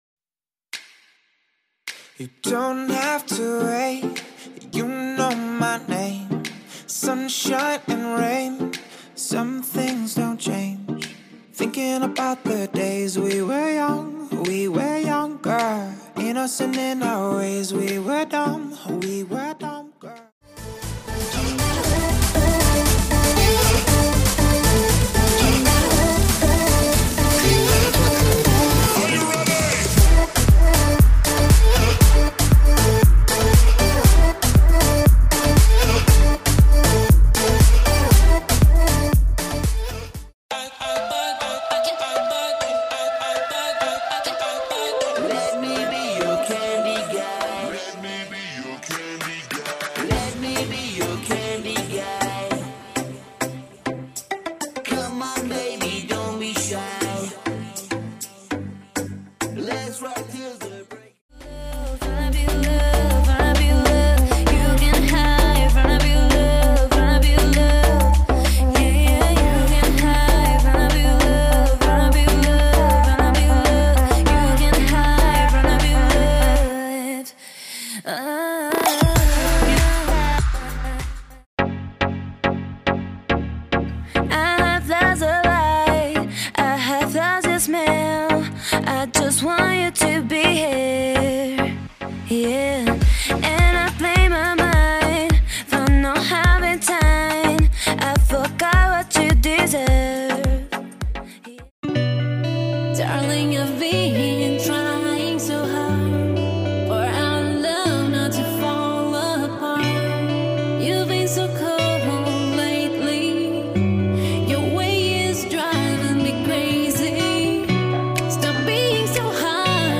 styl - pop/clubbing/dance